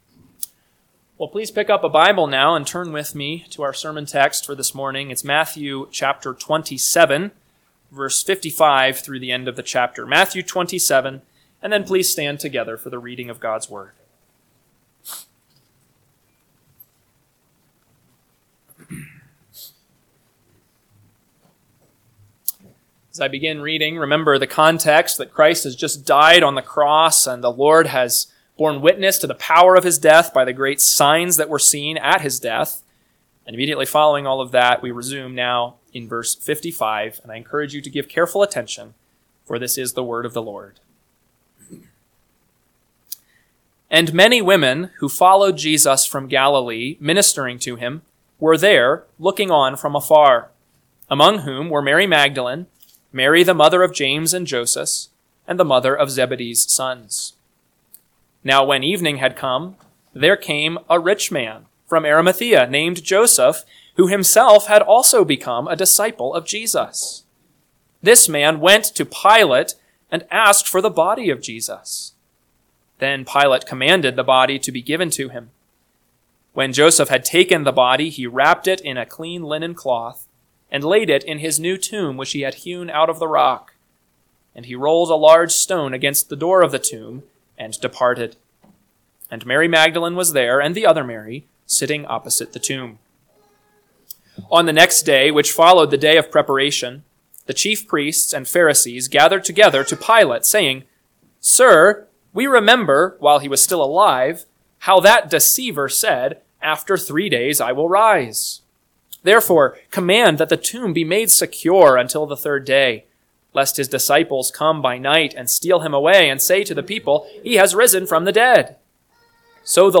AM Sermon – 5/18/2025 – Matthew 27:55-66 – Northwoods Sermons